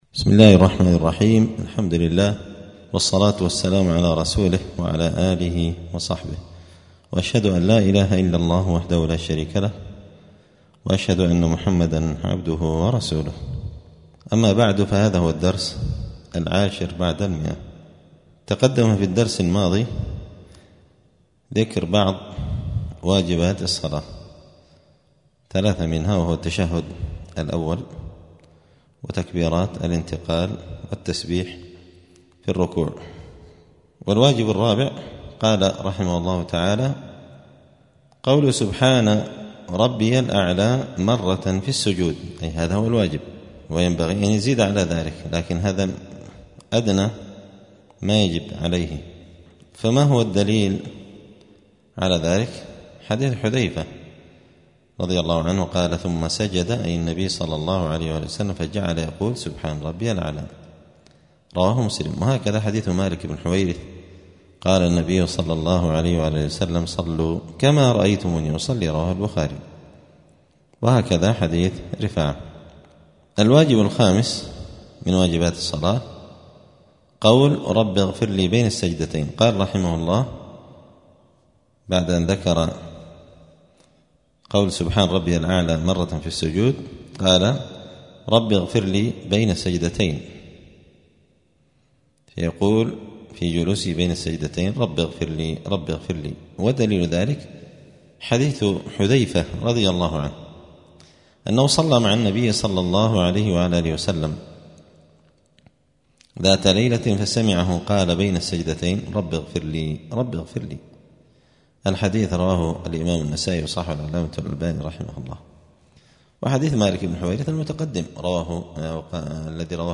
*الدرس العاشر بعد المائة (110) {كتاب الصلاة باب صفة الصلاة من واجبات الصلاة قول سبحان ربي الأعلى في السجود}*
دار الحديث السلفية بمسجد الفرقان قشن المهرة اليمن